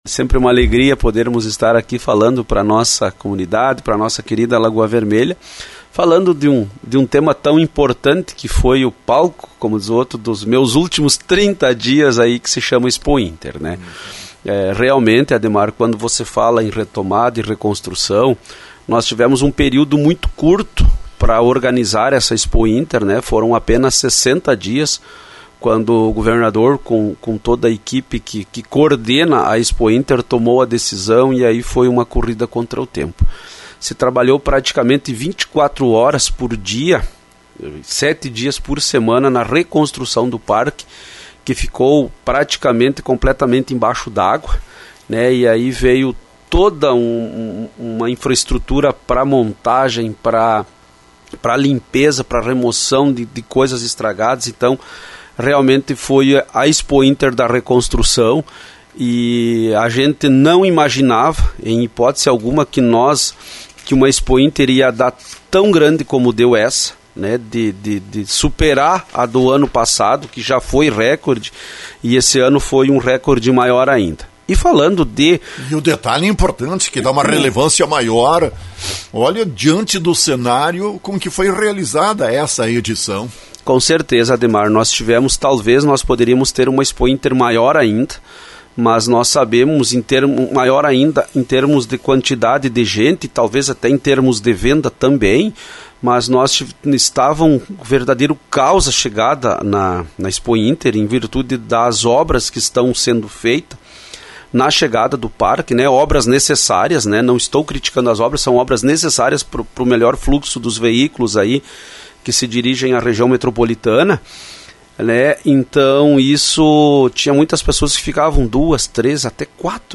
É o que comenta Lindomar do Carmo Morais, secretário-adjunto da secretaria estadual do desenvolvimento Rural.